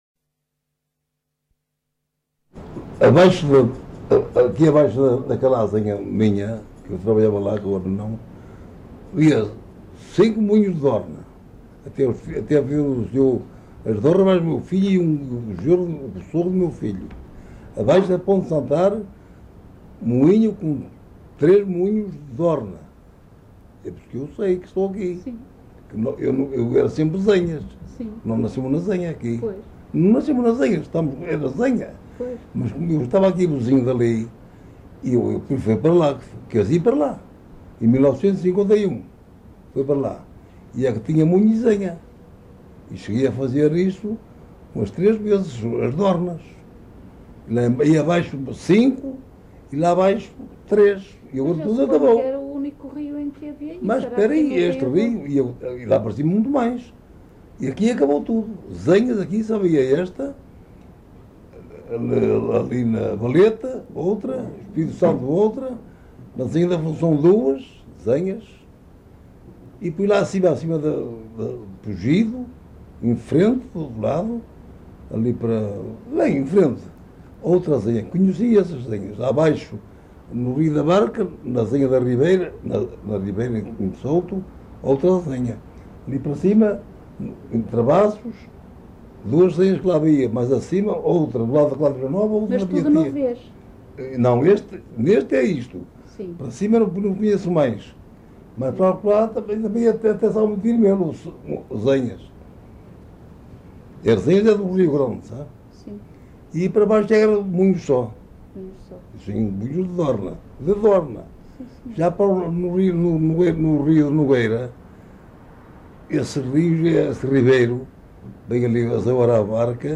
LocalidadeArcos de Valdevez (Arcos de Valdevez, Viana do Castelo)